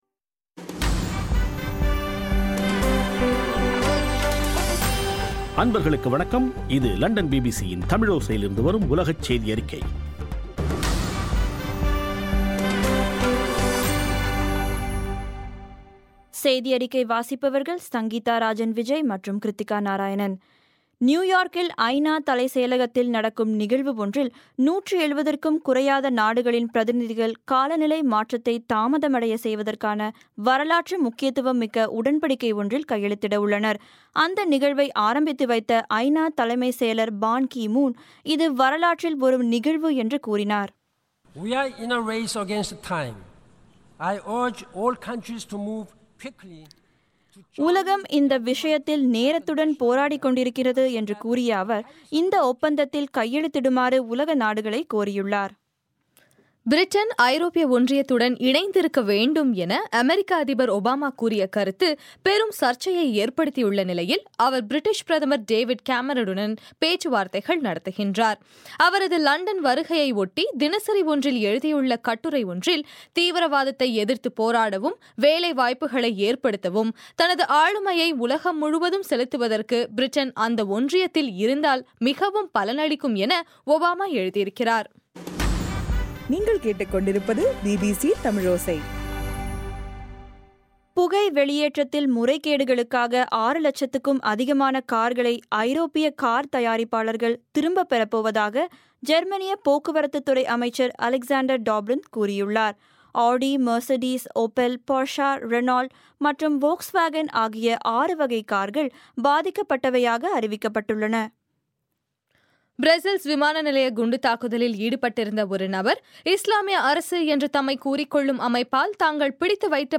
பிபிசி தமிழோசை- உலகச் செய்தியறிக்கை- ஏப்ரல் 22